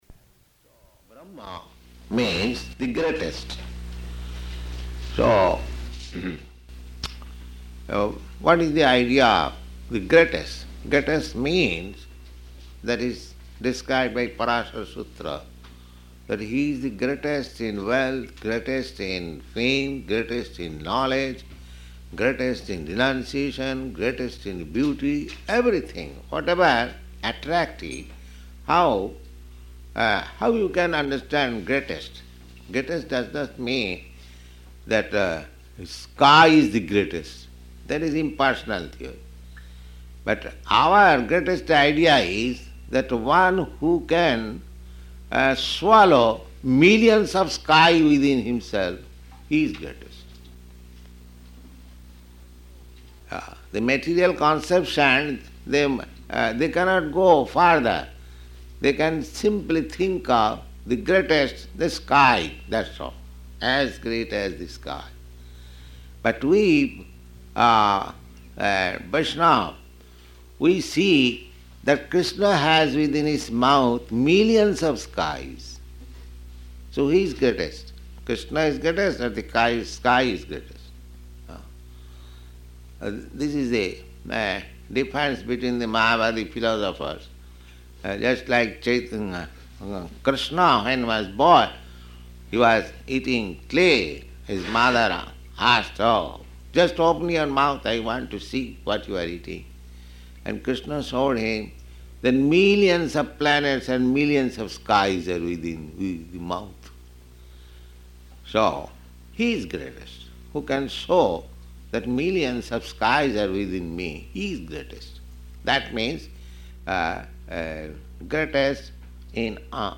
Śrī Caitanya-caritāmṛta, Ādi-līlā 7.108 --:-- --:-- Type: Caitanya-caritamrta Dated: February 18th 1967 Location: San Francisco Audio file: 670218CC-SAN_FRANCISCO.mp3 Prabhupāda: So, Brahman means "the greatest."